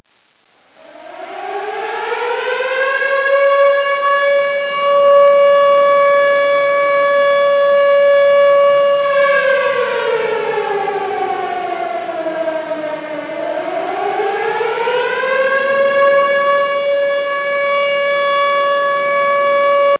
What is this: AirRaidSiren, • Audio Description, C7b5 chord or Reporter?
AirRaidSiren